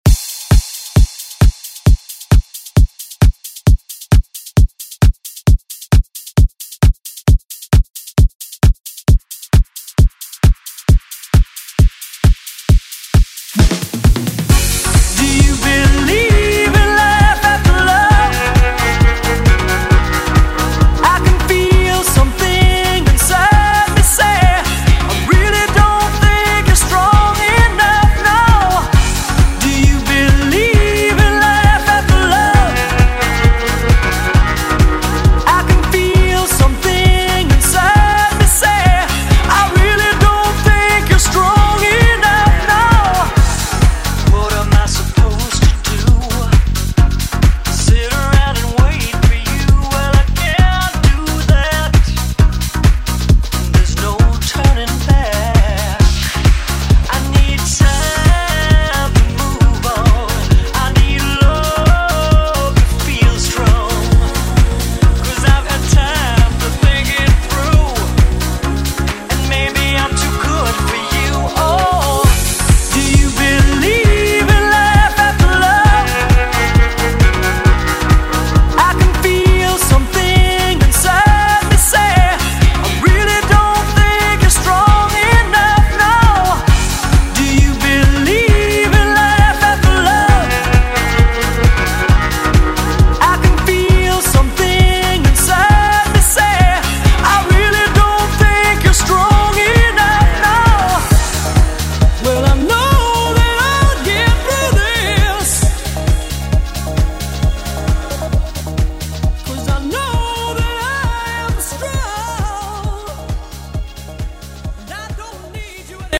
Electronic Pop Music Extended Club ReWork
120 bpm
Genre: DANCE